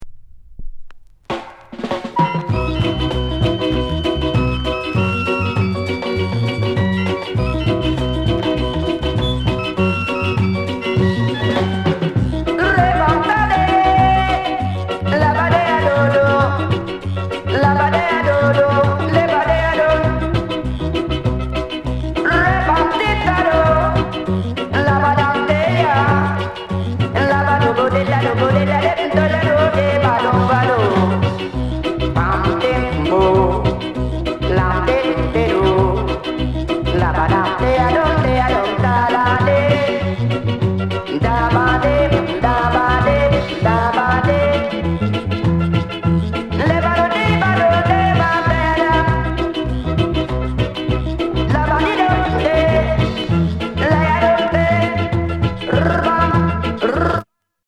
SKINHEAD INST